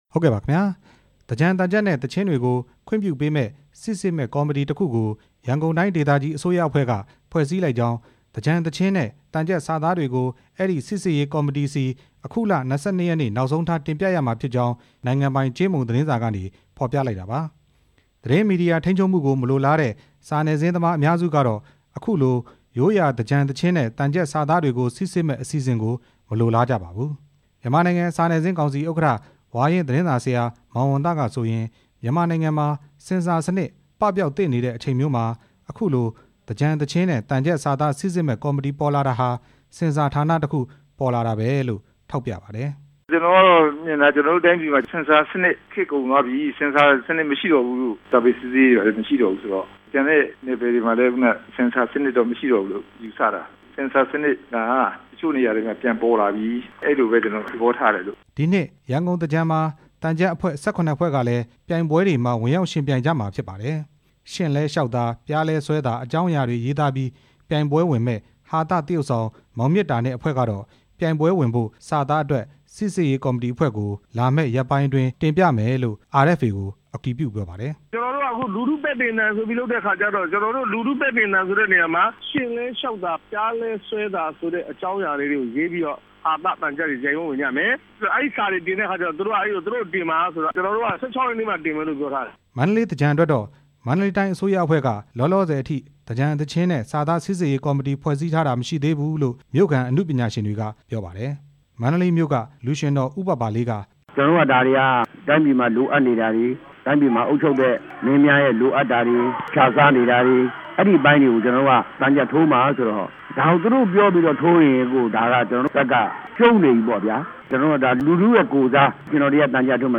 သြင်္ကန်သံချပ် စိစစ်မှုနဲ့ ပတ်သက်ပြီး တင်ပြချက်